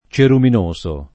ceruminoso [ © erumin 1S o ] agg.